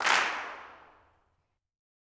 CLAPS 10.wav